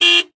th_sfx_car_horn.ogg